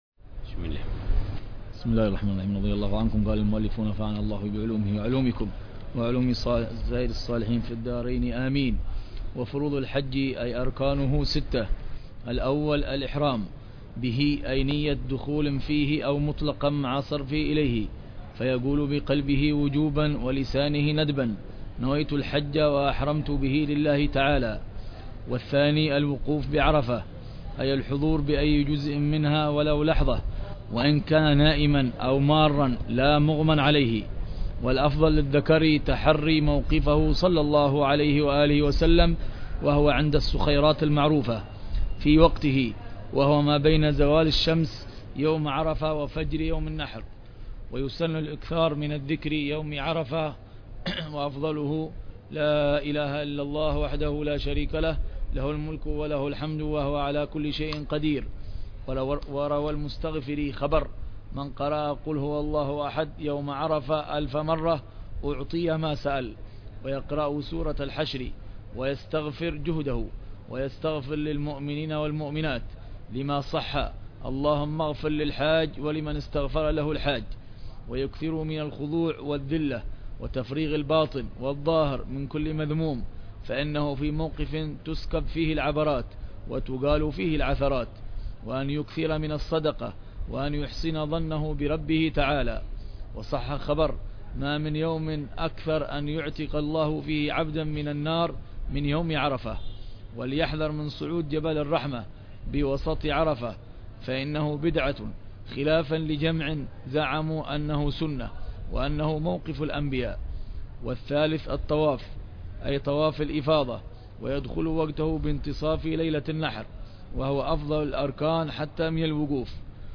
شرح الحبيب عمر بن حفيظ على كتاب كفاية الراغب شرح هداية الطالب إلى معرفة الواجب للإمام العلامة عبد الله بن الحسين بن عبد الله بلفقيه.